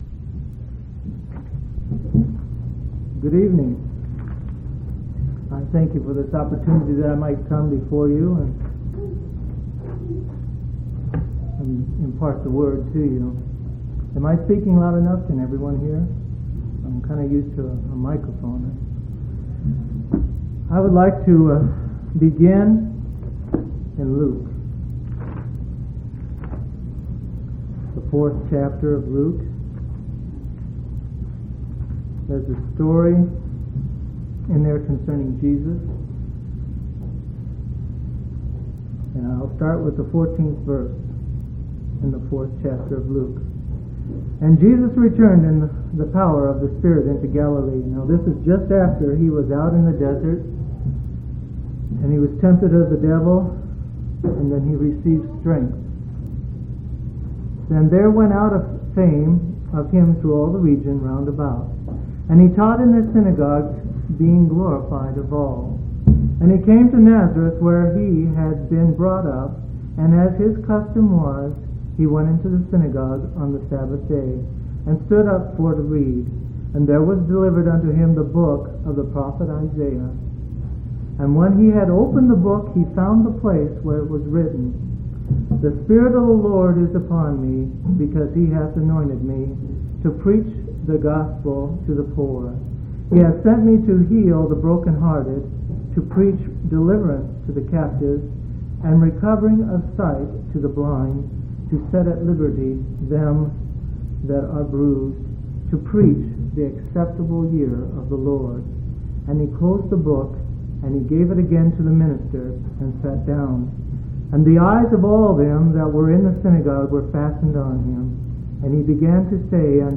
2/13/1983 Location: Grand Junction Local Event